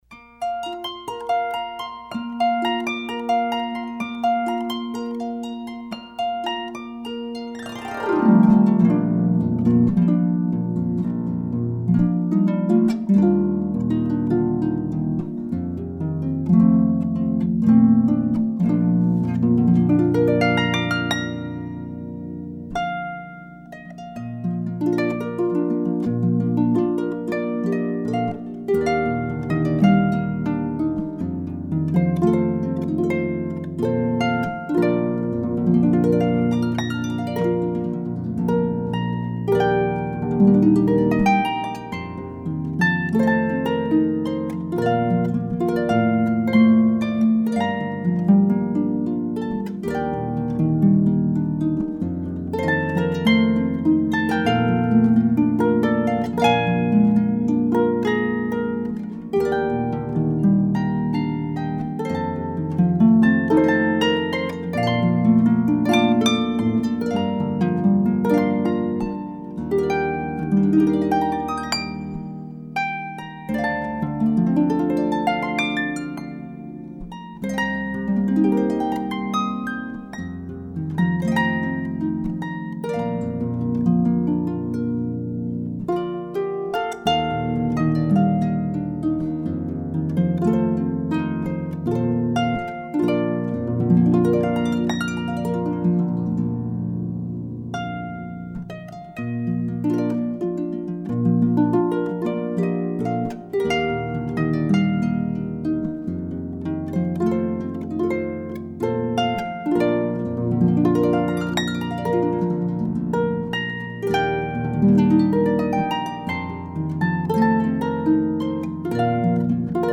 Harpist 2
harp2-7.mp3